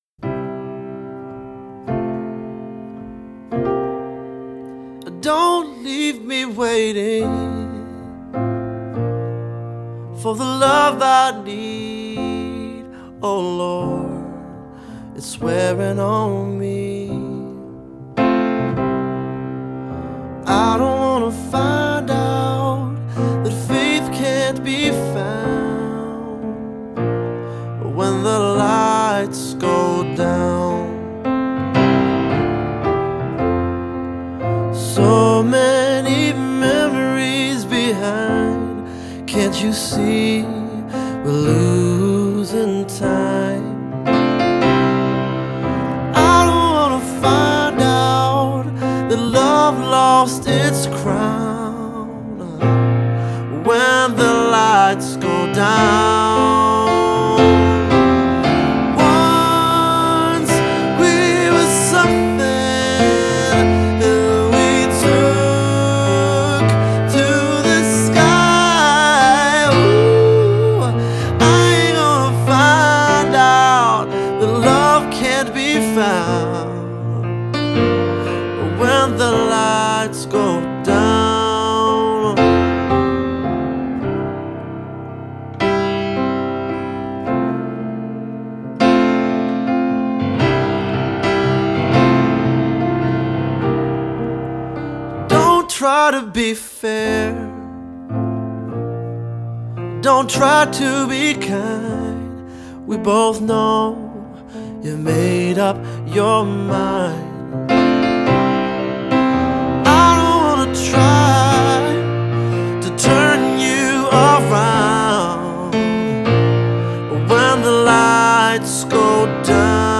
This one’s feels like a soulful gospel song.